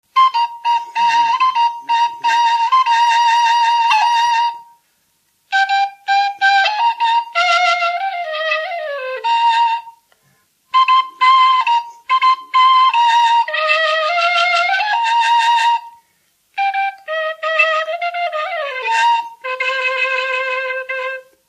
Dallampélda: Hangszeres felvétel
Erdély - Csík vm. - Csíkjenőfalva
furulya Műfaj: Asztali nóta Gyűjtő